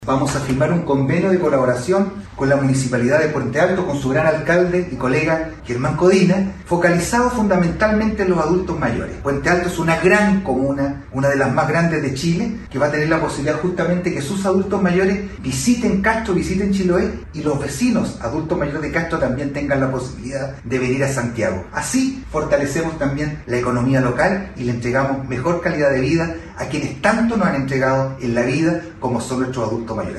En ese sentido Vera agradeció la buena disposición de su colega puentealtino precisando que el convenio que se suscribiría consiste principalmente en que delegaciones de adultos mayores de ambas comunas puedan recorrer los principales lugares turísticos con la finalidad de incentivar la economía local, pero también como una forma de entregar una posibilidad cierta a los adultos mayores para que disfruten de las bondades paisajísticas, económicas y de desarrollo que cada una de las comunas tiene: